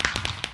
firework7.mp3